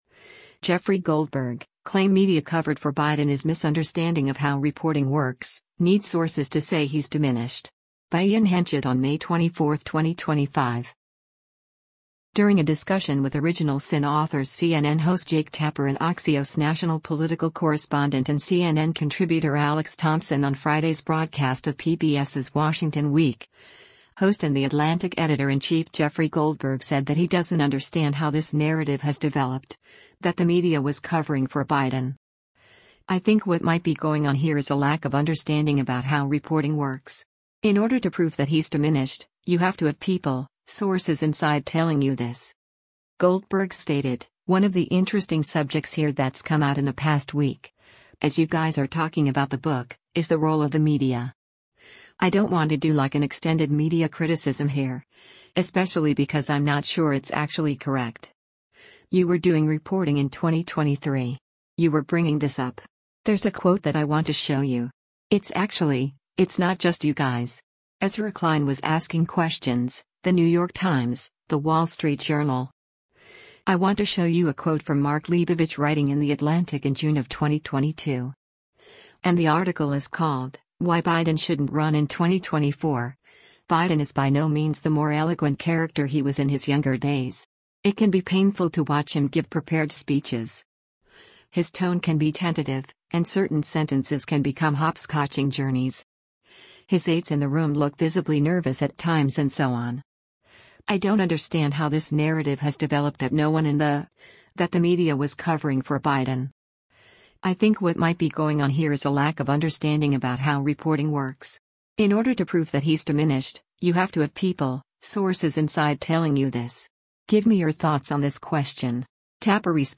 During a discussion with “Original Sin” authors CNN host Jake Tapper and Axios National Political Correspondent and CNN Contributor Alex Thompson on Friday’s broadcast of PBS’s “Washington Week,” host and The Atlantic Editor-in-Chief Jeffrey Goldberg said that he doesn’t “understand how this narrative has developed…that the media was covering for Biden.